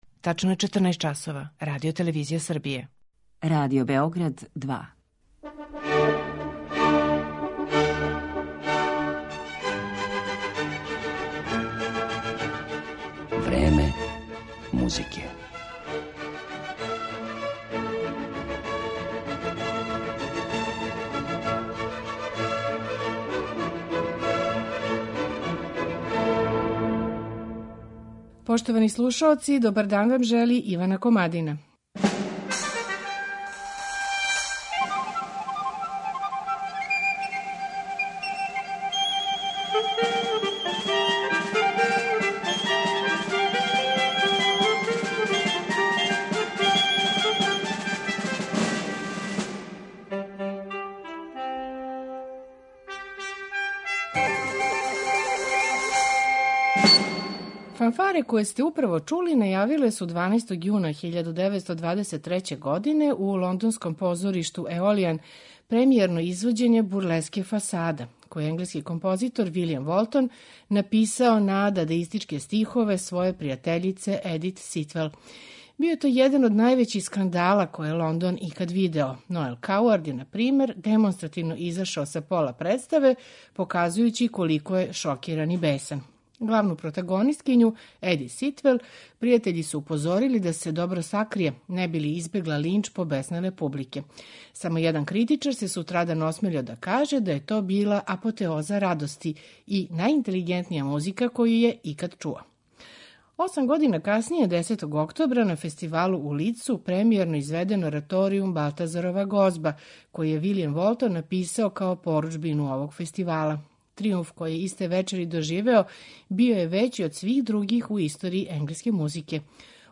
У данашњем Времену музике покушаћемо да одговоримо шта је то што је Волтона непрестано водило од катастрофе до тријумфа. Слушаћете његове концерте за виолилну и виолу, бурлеску "Фасада", ораторијум "Балтазарова гозба", "Крунидбени марш".